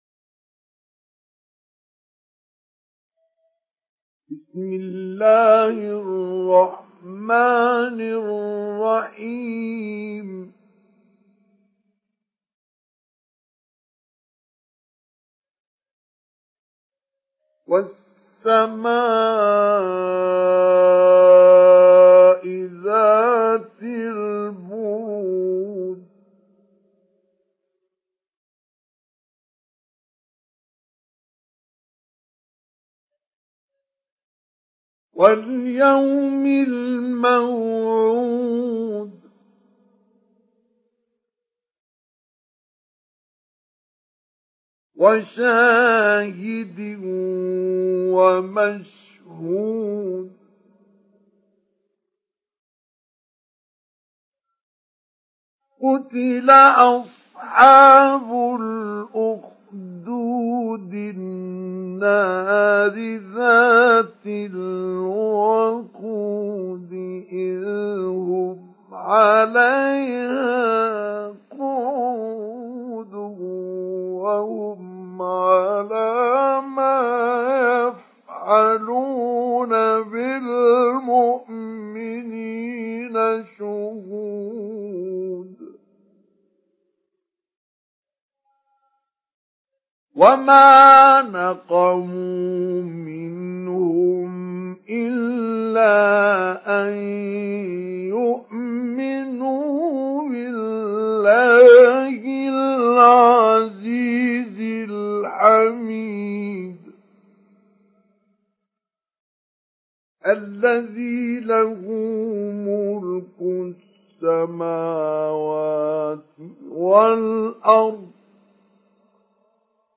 Sourate Al-Buruj MP3 à la voix de Mustafa Ismail Mujawwad par la narration Hafs
Une récitation touchante et belle des versets coraniques par la narration Hafs An Asim.